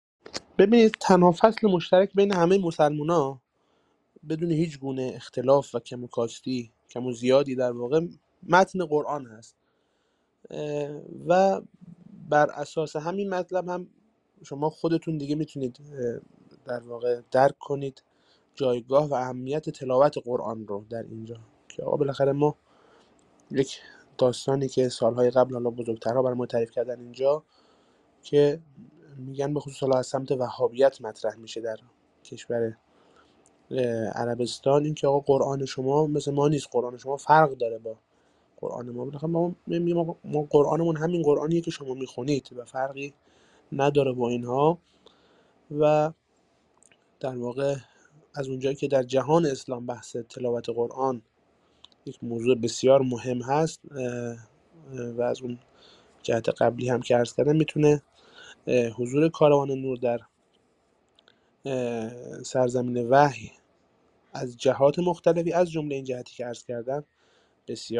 Рӯзҳои пурҷушу хуруши корвони Қуръонии Ҳаҷ; Зоирон аз қироати қориёни эронӣ истиқбол карданд + садо
Тегҳо: Корвон ، Қориён ، Тиловати Қуръон